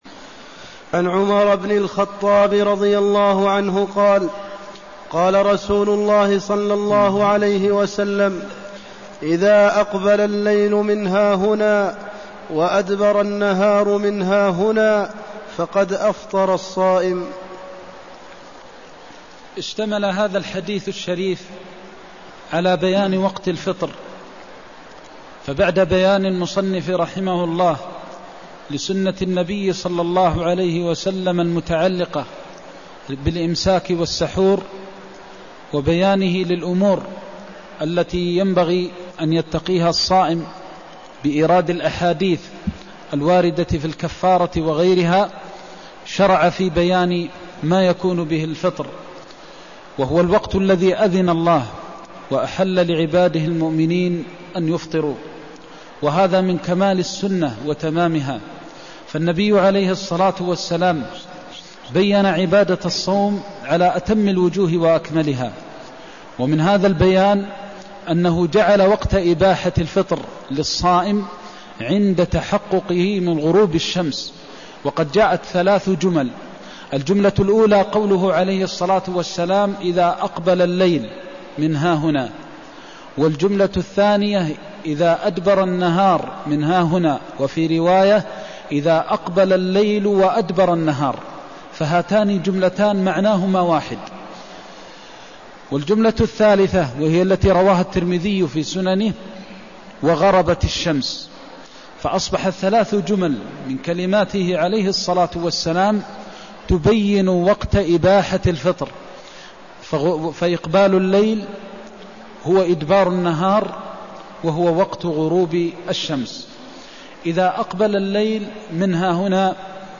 المكان: المسجد النبوي الشيخ: فضيلة الشيخ د. محمد بن محمد المختار فضيلة الشيخ د. محمد بن محمد المختار متى يحل فطر الصائم (186)